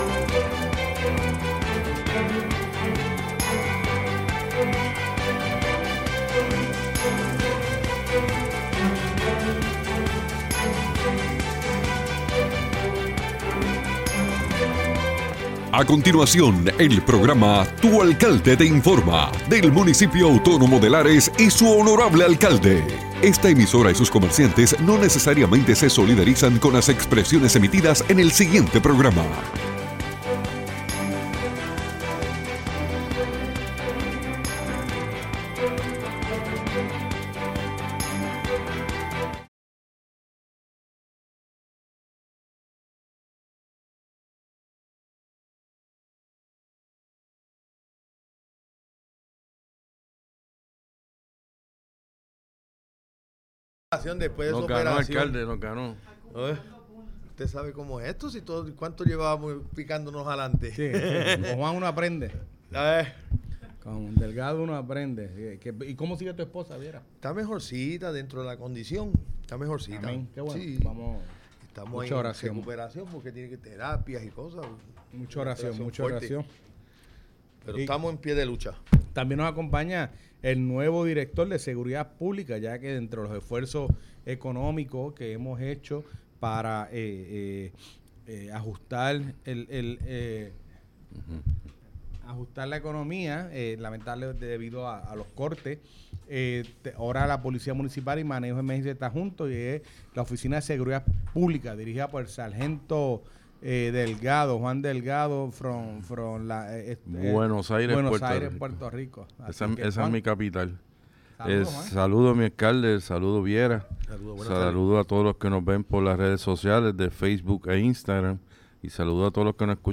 El honorable alcalde de Lares, Fabian Arroyo y su equipo de trabajo nos informan sobre lo acontece en el pueblo de Lares.